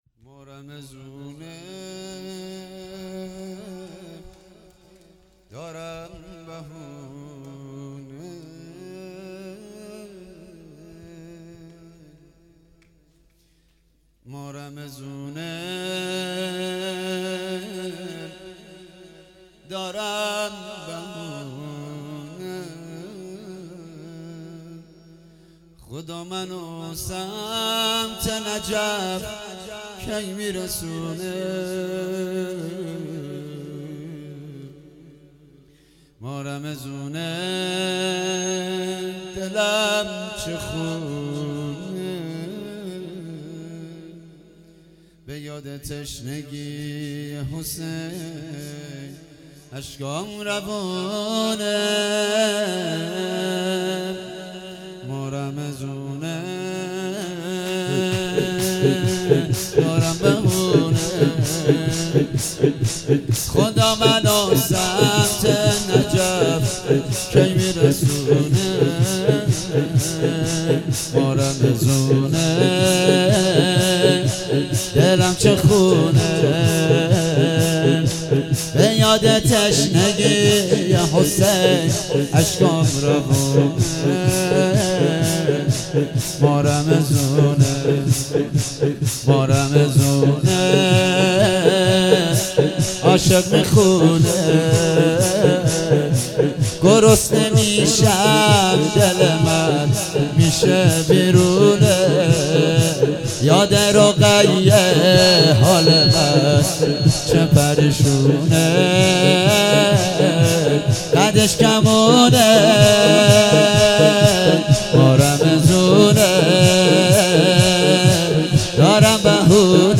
0 0 هفتگی 12 خرداد -زمینه
مداحی